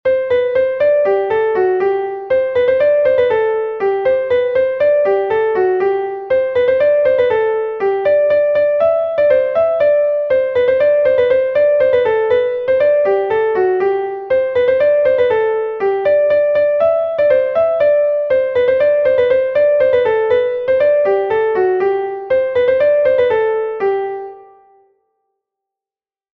Bale de Bretagne